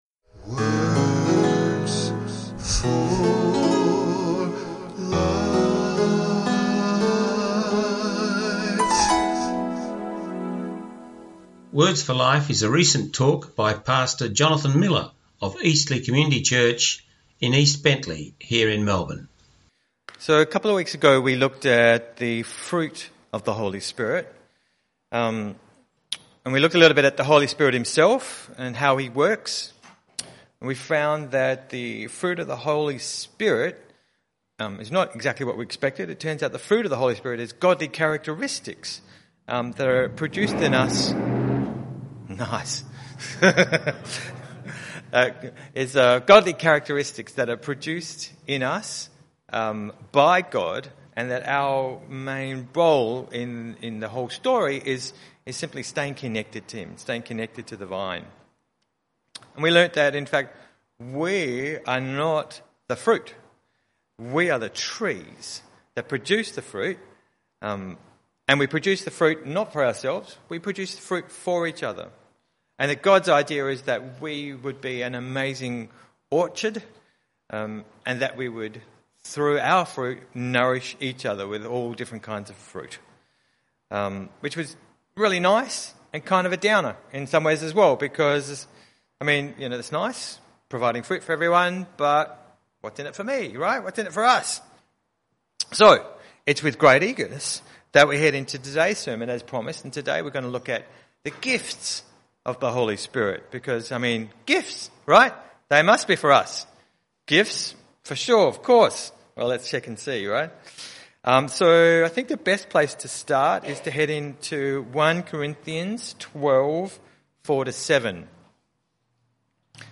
radio talks